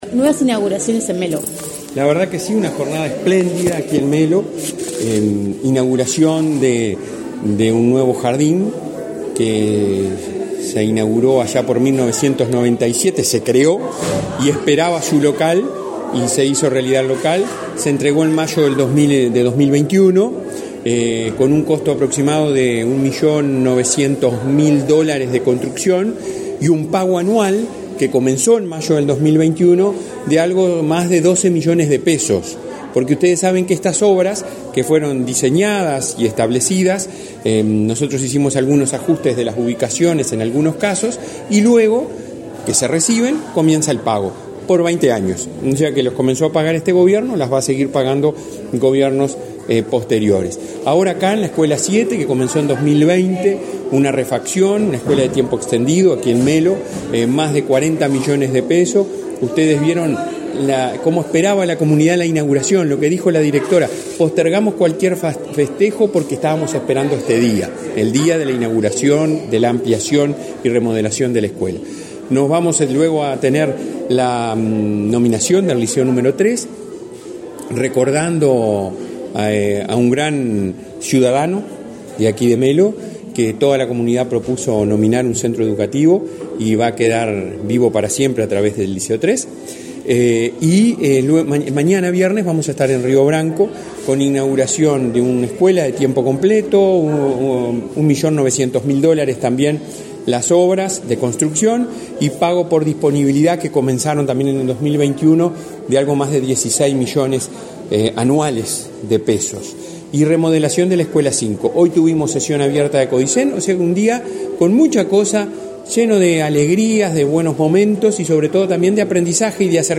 Entrevista al presidente de la ANEP, Robert Silva
Entrevista al presidente de la ANEP, Robert Silva 17/08/2023 Compartir Facebook X Copiar enlace WhatsApp LinkedIn La Administración Nacional de Educación Pública (ANEP) concretó obras en cuatro centros educativos de Cerro Largo, este 17 de agosto. Tras las inauguraciones, el presidente de la ANEP, Robert Silva, realizó declaraciones a Comunicación Presidencial.